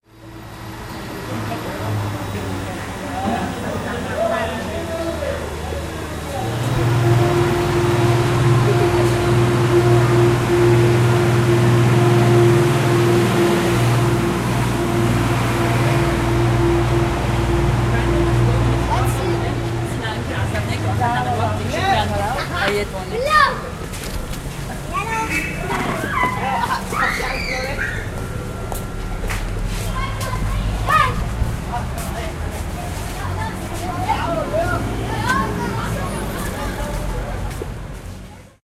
syriast-ambient_market.ogg